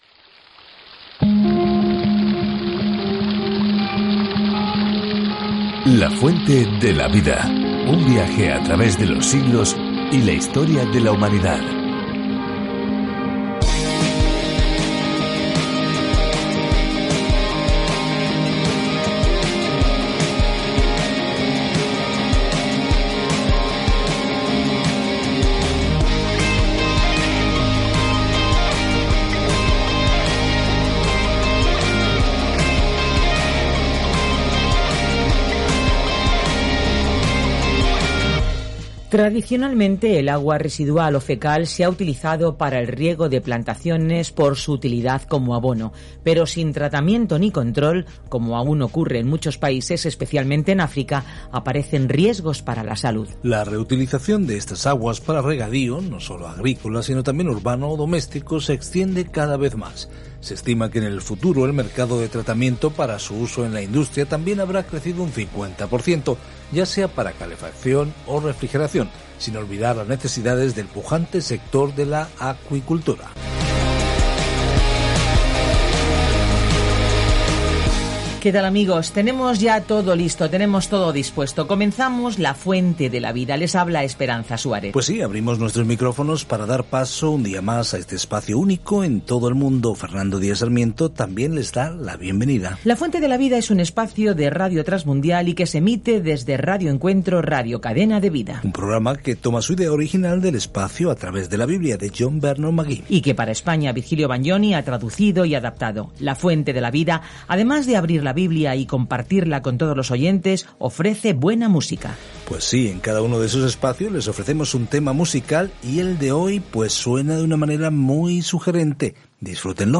Escritura DANIEL 5:1-19 Día 12 Iniciar plan Día 14 Acerca de este Plan El libro de Daniel es a la vez una biografía de un hombre que creyó en Dios y una visión profética de quién eventualmente gobernará el mundo. Viaja diariamente a través de Daniel mientras escuchas el estudio de audio y lees versículos seleccionados de la palabra de Dios.